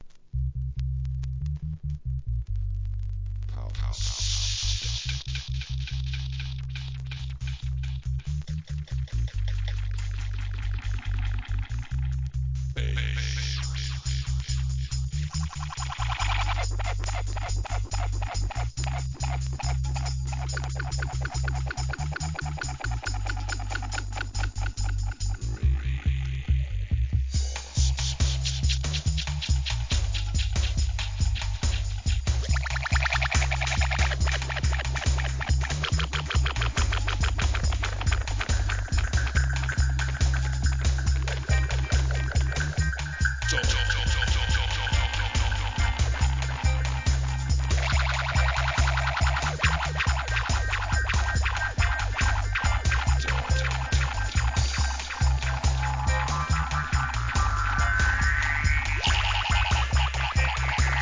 REGGAE
不協和音が響く1996年DUB ALBUM!!